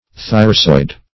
Search Result for " thyrsoid" : The Collaborative International Dictionary of English v.0.48: Thyrsoid \Thyr"soid\ (th[~e]r"soid), Thyrsoidal \Thyr*soid"al\ (th[~e]r*soid"al), a. [Gr.
thyrsoid.mp3